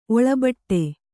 ♪ oḷabaṭṭe